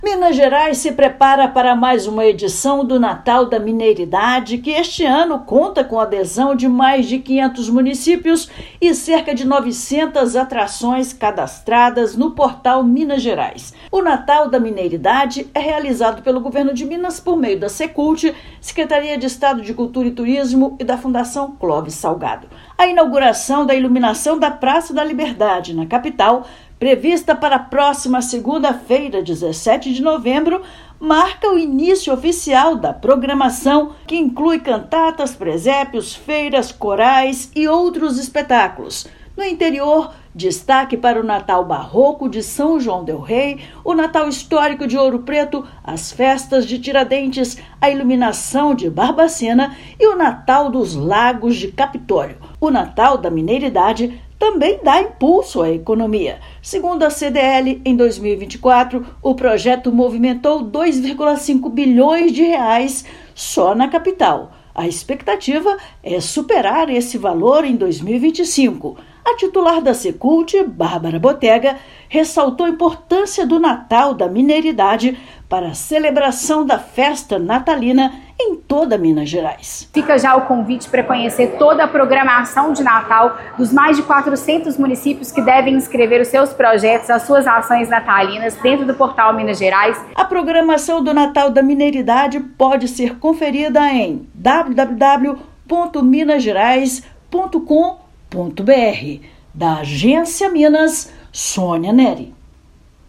Edição deste ano amplia o alcance, aposta em experiências imersivas e reforça Minas como o destino natalino mais completo do Brasil. Ouça matéria de rádio.